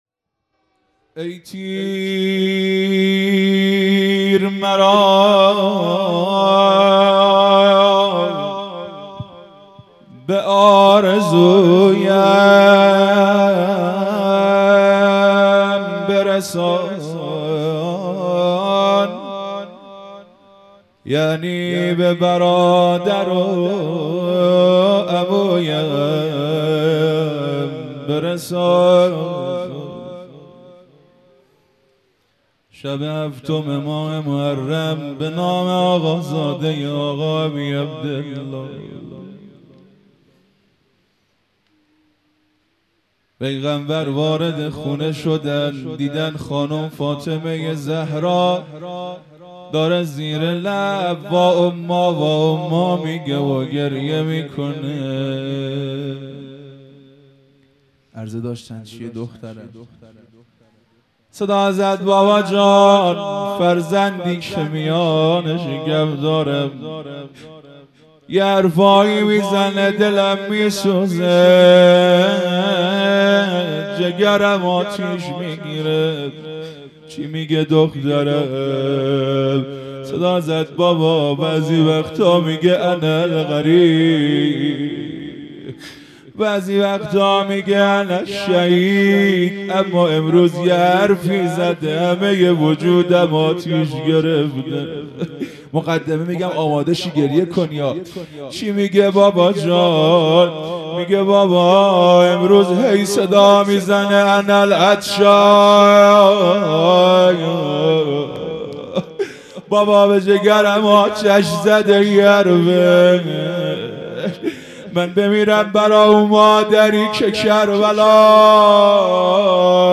روضه
مراسم عزاداری محرم الحرام ۱۴۴۳_شب هفتم